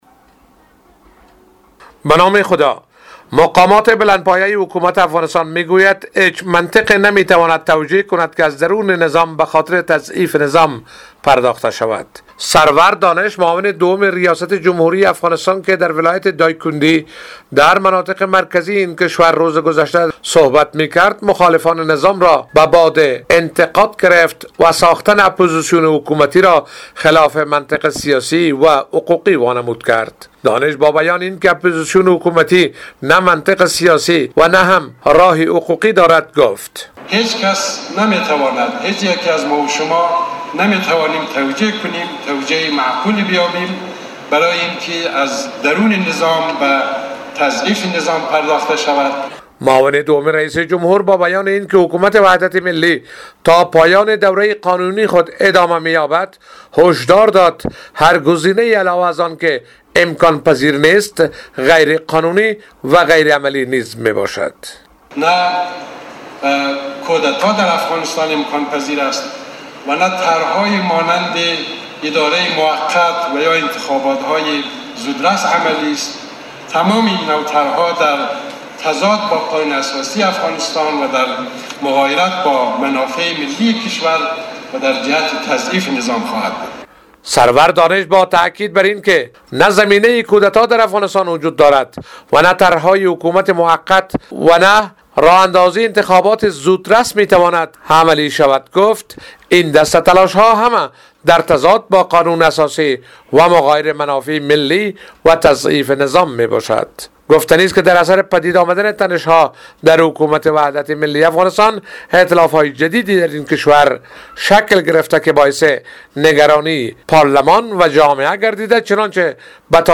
جزئیات این خبر در گزارش همکارمان